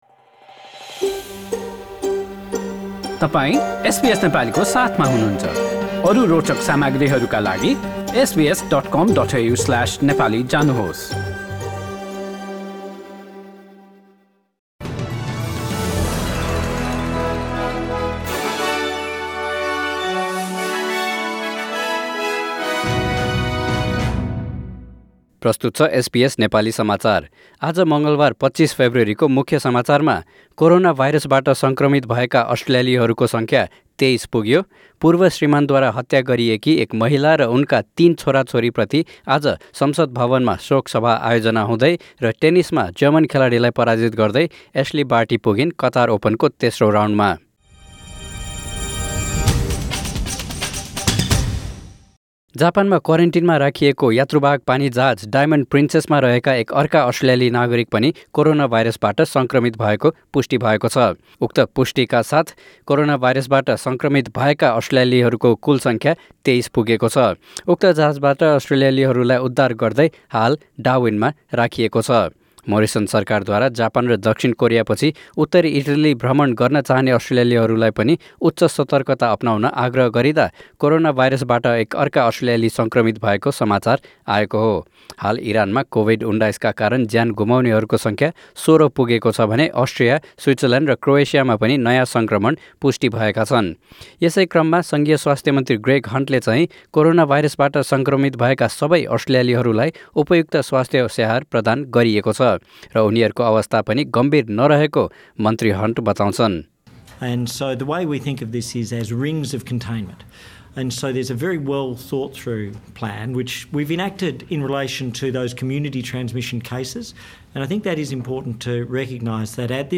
एसबीएस नेपाली अस्ट्रेलिया समाचार: बुधवार २६ फेब्रुअरी २०२०